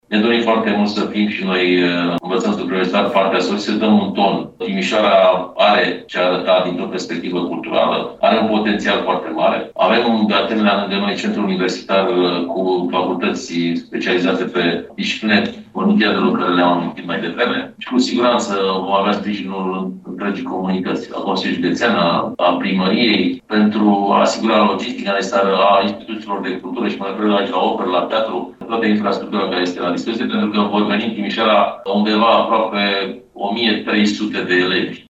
Reprezentanții Inspectoratului Școlar Județean au anunțat Ministerul Educației că doresc să găzduiască fazele concursurilor școlare vocaționale, mai ales în contextul programului Timișoara-Capitală Culturală Europeană, spune inspectorul școlar general, Marin Popescu